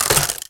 player_takes_damage.ogg